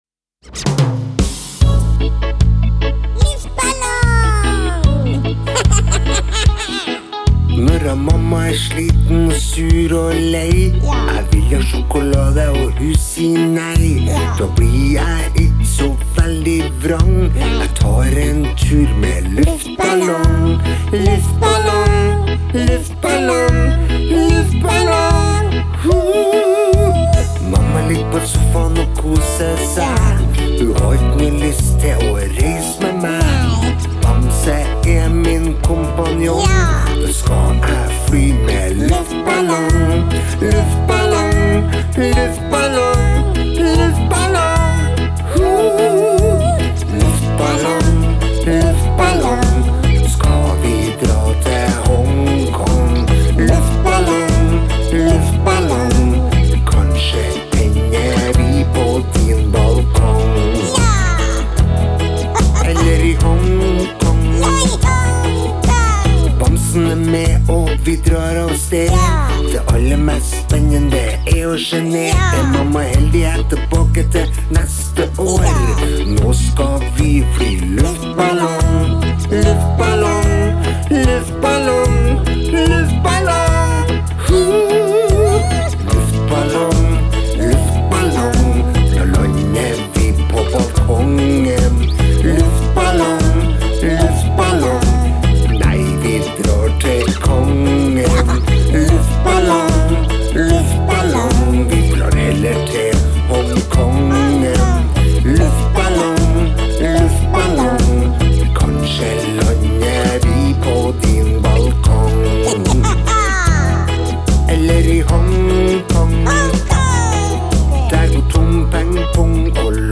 Fredag 14. januar 2022: LUFTBALLONG – BARNESANG (Sang nr 135 – på 135 dager)